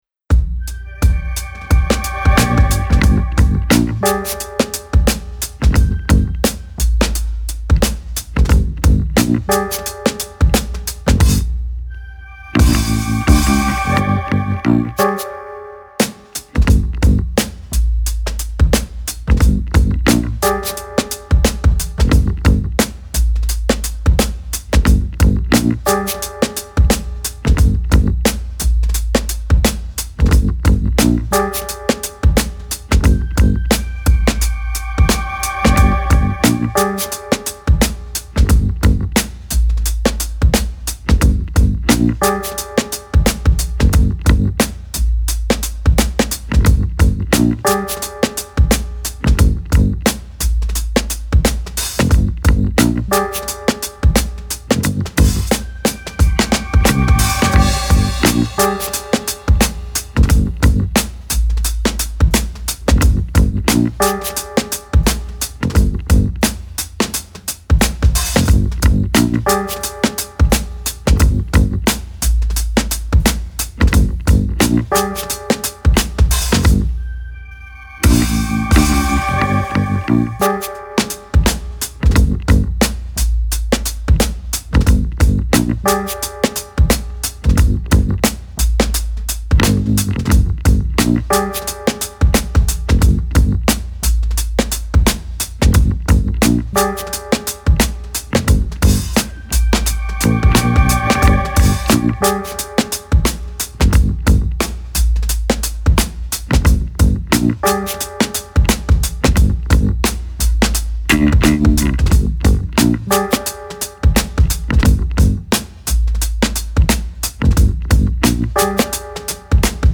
Funky agent style with cool groove and hip hop beds.